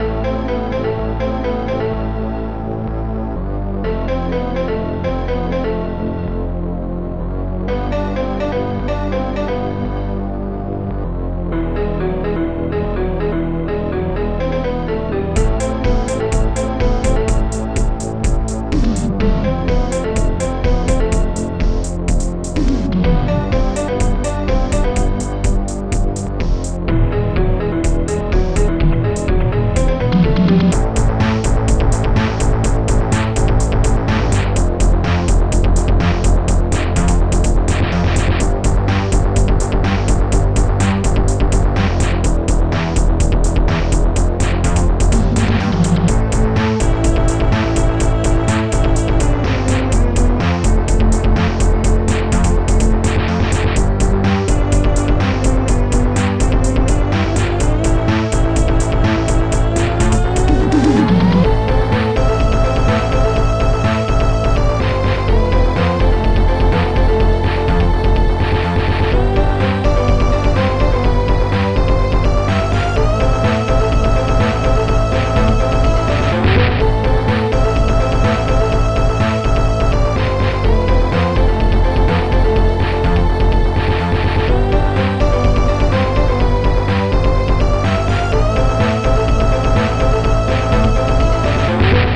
Protracker and family
panfloeten